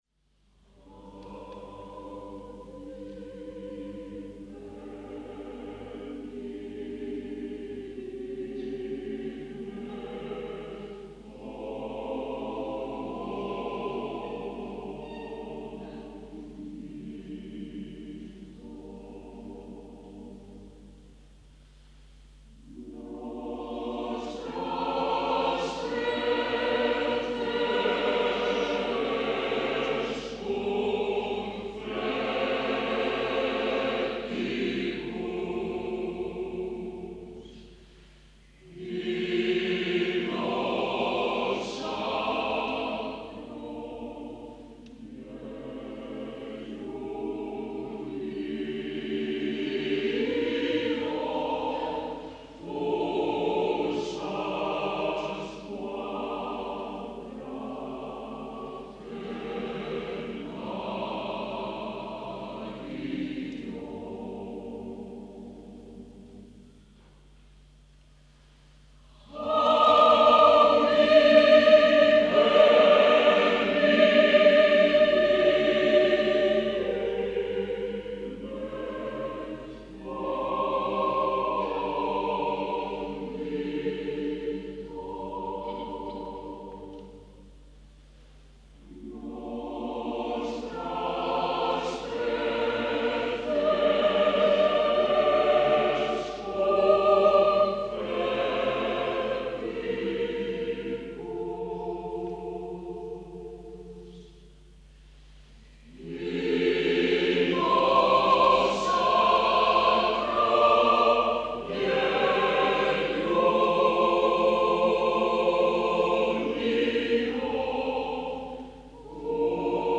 Día Coral.